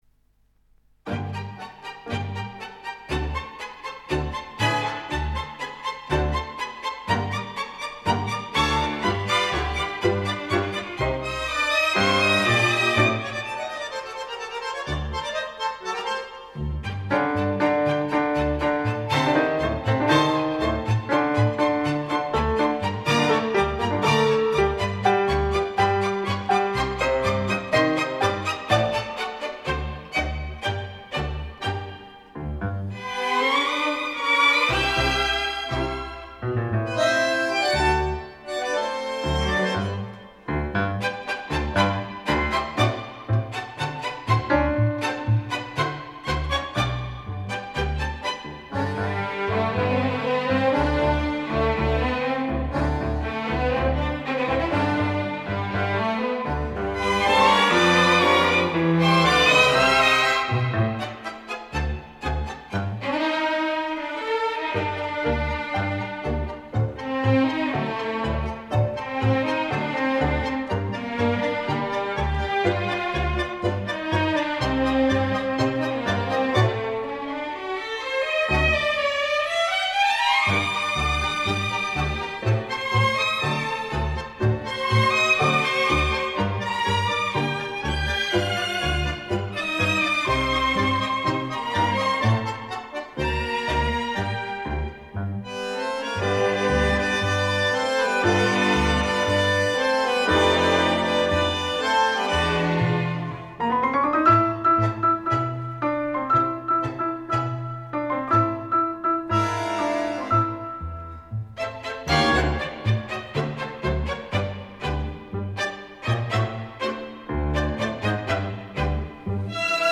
Танго